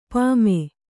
♪ pāme